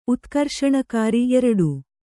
♪ utkarṣakāri